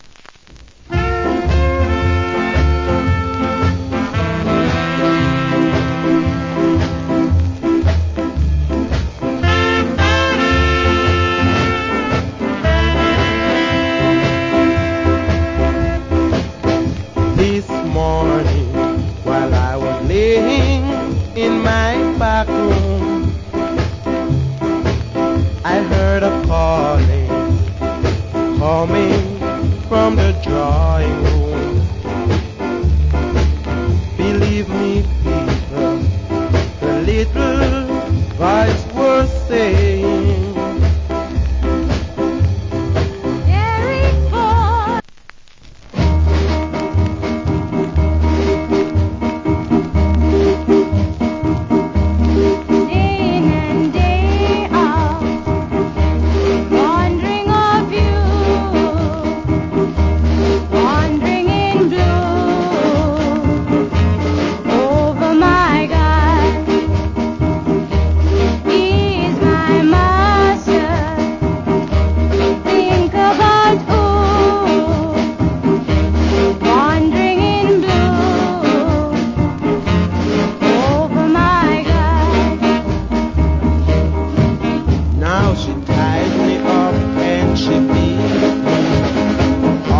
Nice Ska Vocal.